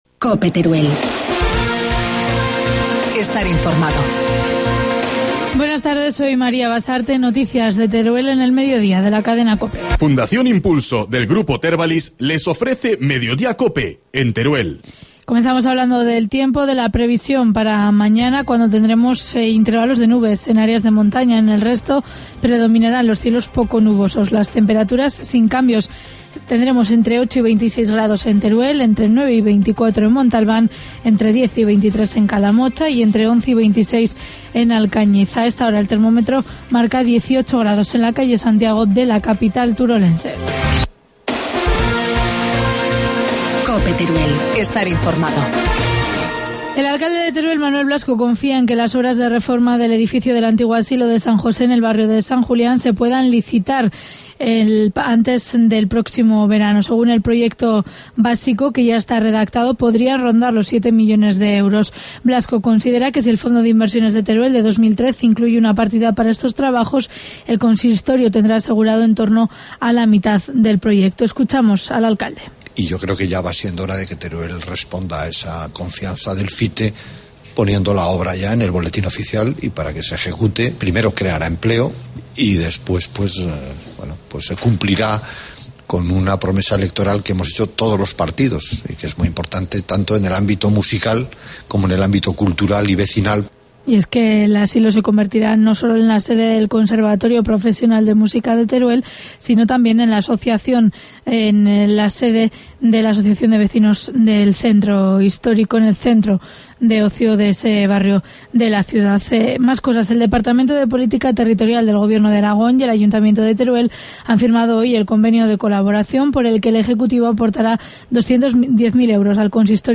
Informativo mediodía, lunes 15 de abril